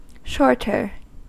Ääntäminen
Ääntäminen US : IPA : [ˈʃɔɹ.tɚ] Tuntematon aksentti: IPA : /ˈʃɔː.tə(ɹ)/ Haettu sana löytyi näillä lähdekielillä: englanti Shorter on sanan short komparatiivi.